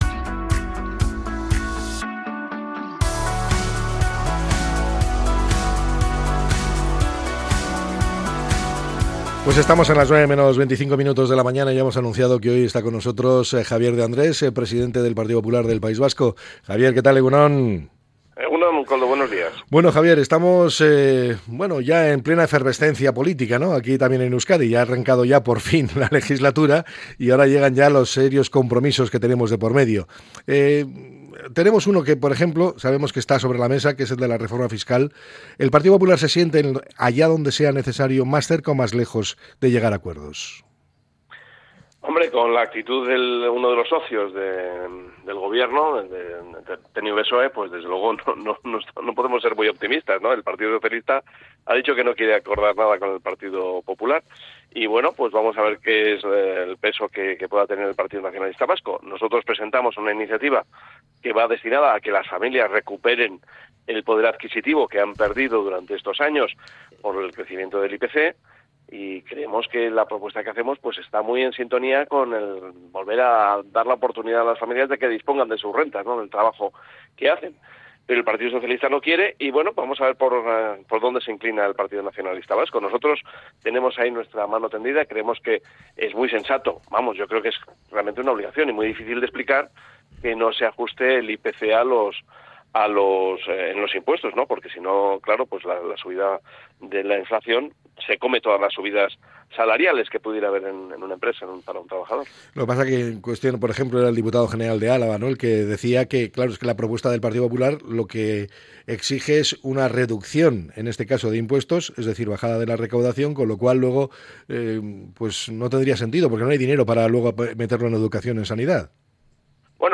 Entrevista al presidente del PP Vasco para abordar temas clave de la política vasca